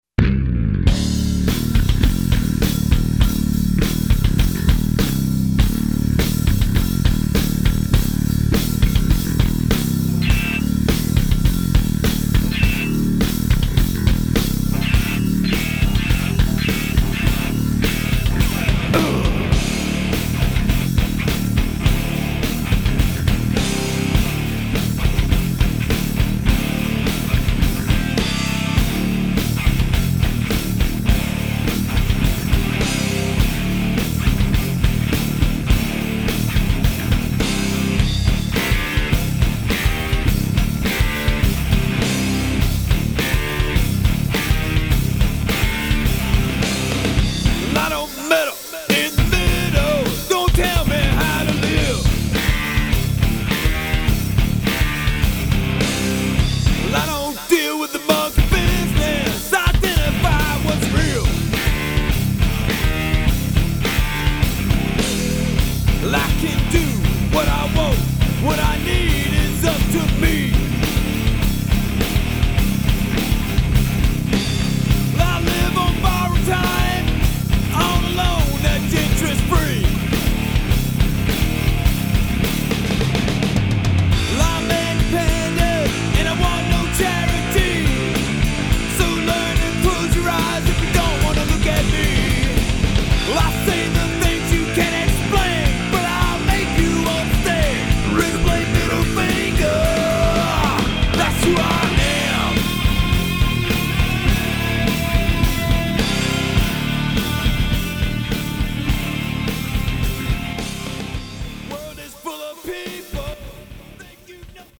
13 tracks of hard-rockin' mayhem!!!